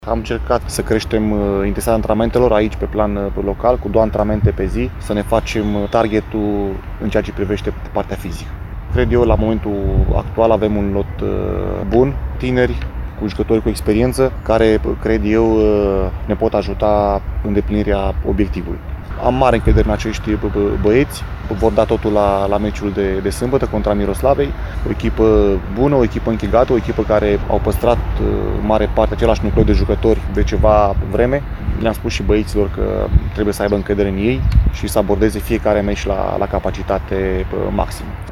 Antrenorul principal DORIN GOIAN a declarat că acest campionat va fi neobișnuit, având în vedere condițiile de desfășurare a meciurilor, dar s-a arătat mulțumit de valoarea lotului.